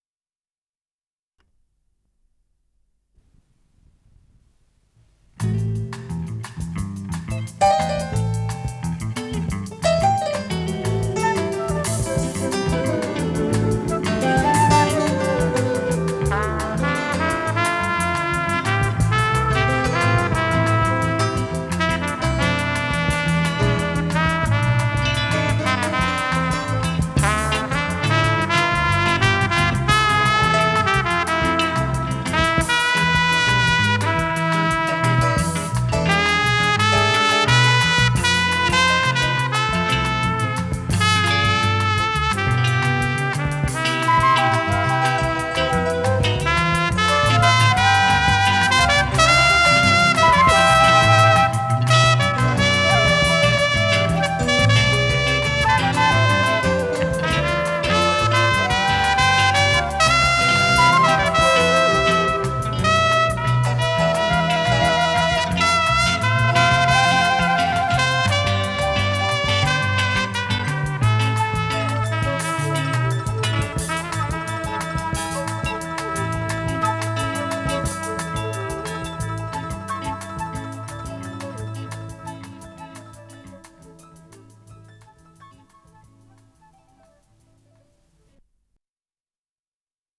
ПодзаголовокЗаставка, ре минор
ВариантДубль моно